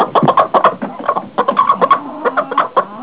Galline
galline.wav